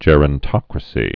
(jĕrən-tŏkrə-sē)